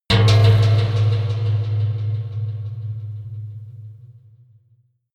Gemafreie Sounds: Impacts